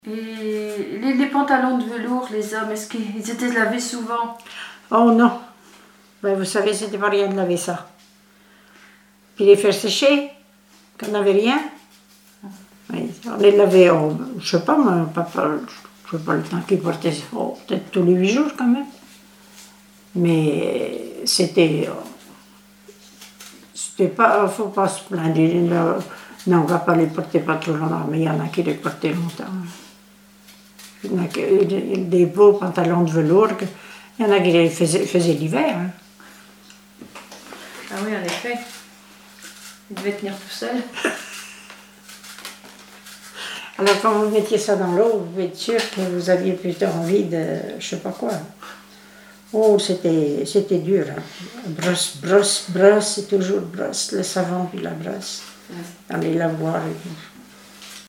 Enquête Arexcpo en Vendée-Pays Sud-Vendée
Témoignages sur les tâches ménagères
Catégorie Témoignage